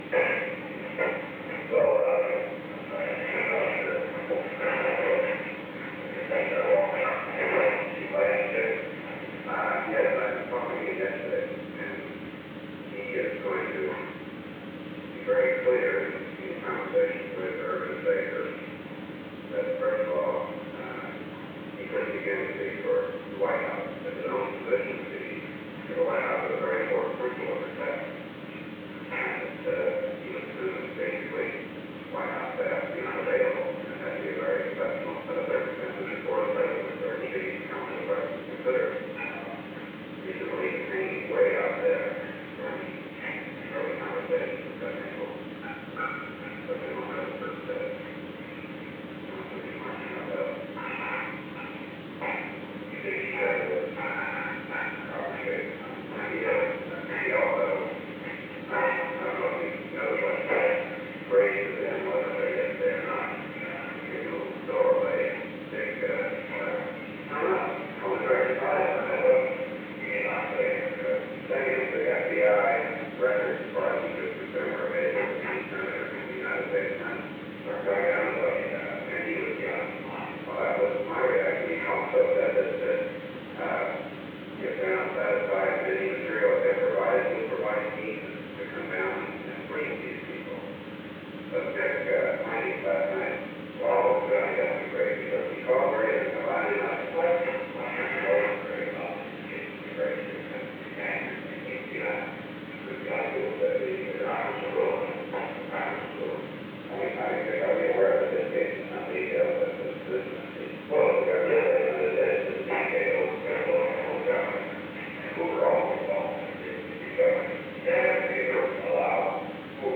Conversation No. 442-5 Date: June 4, 1973 Time: Unknown between 11:30 am and 11:52 am Location: Executive Office Building The President played a portion of a recording of a meeting with John W. Dean, III.